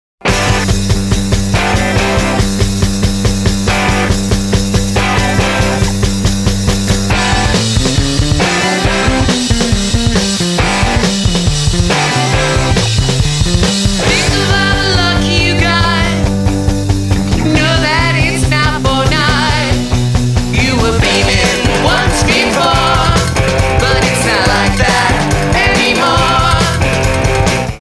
• Качество: 320, Stereo
мужской вокал
саундтреки
rock n roll